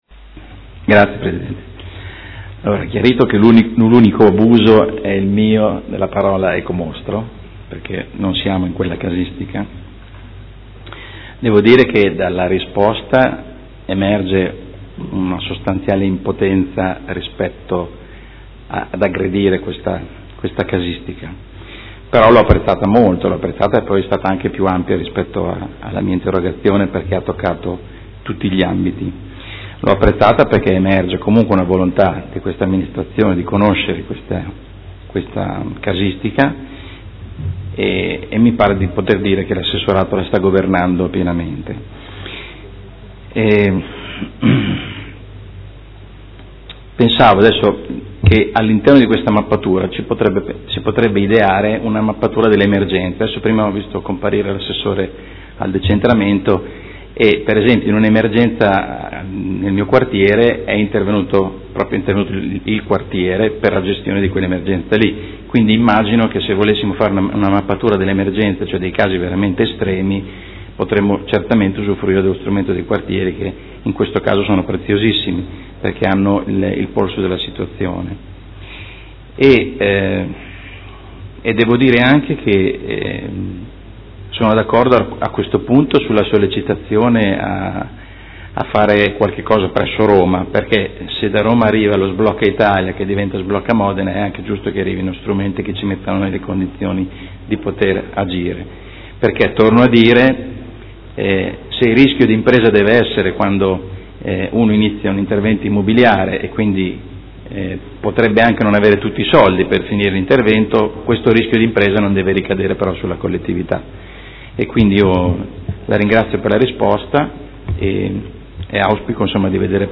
Seduta del 9/06/2016 Replica a risposta dell'Assessora Vandelli. Interrogazione del Consigliere De Lillo (P.D.) avente per oggetto: Per qualche “ecomostro” in meno – Le opportunità per il completamento dei cantieri privati in stato di abbandono